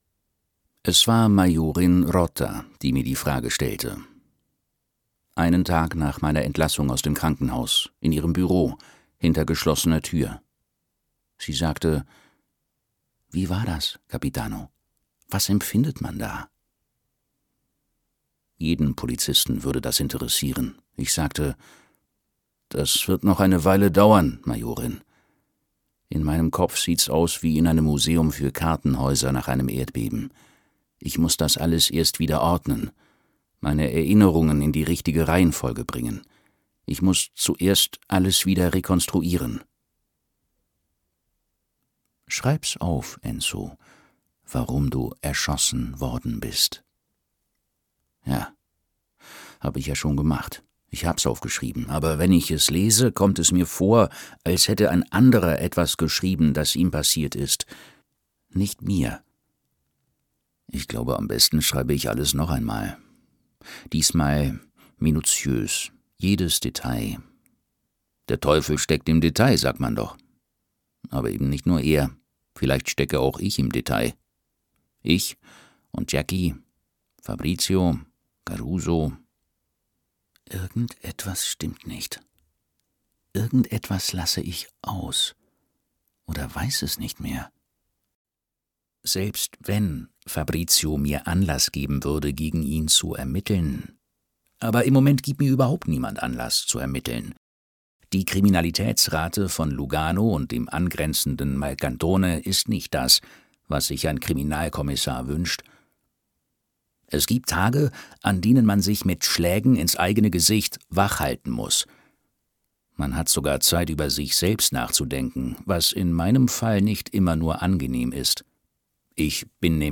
Die Familie sehen und sterben - Toni Rivera | argon hörbuch
Gekürzt Autorisierte, d.h. von Autor:innen und / oder Verlagen freigegebene, bearbeitete Fassung.